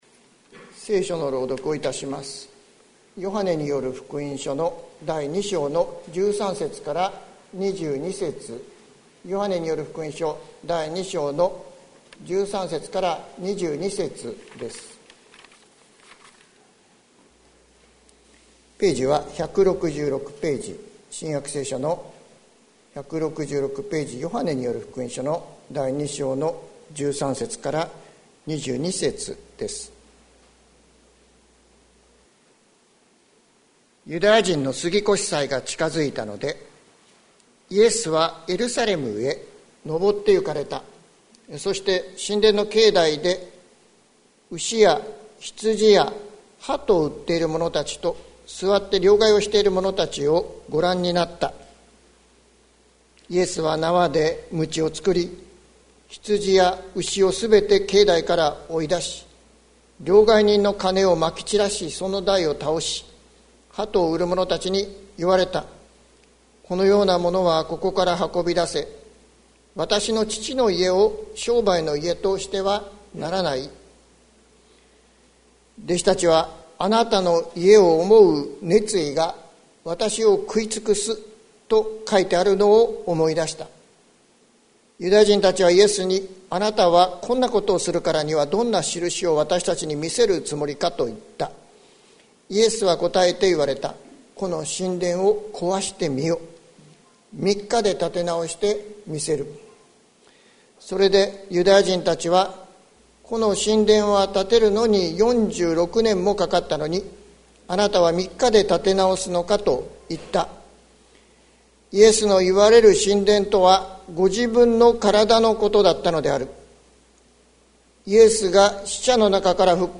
2021年11月21日朝の礼拝「 主との交わりに生かされ」関キリスト教会
説教アーカイブ。